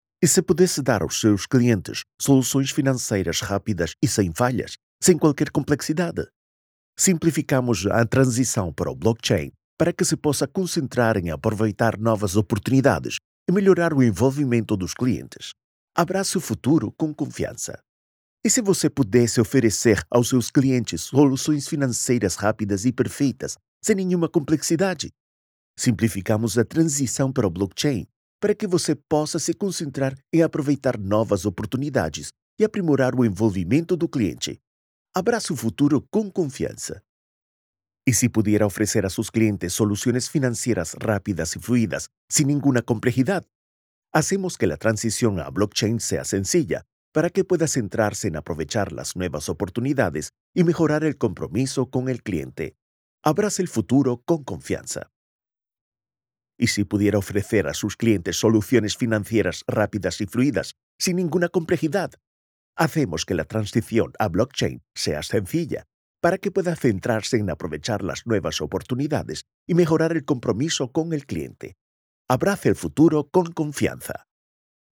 Commercial, Distinctive, Accessible, Versatile, Reliable
Corporate
His voice is often described as fresh, young, calm, warm, and friendly — the “guy next door” — ideal for commercials, audiobooks, narration, promos, YouTube and educational content, e-learning, presentations, and podcasts.
His natural, conversational tone adapts easily to different moods: happy, serious, casual, sad, or energetic.
As a full-time producer, studio owner, and musician, he ensures clean, echo-free, uncompressed audio, delivered in any format.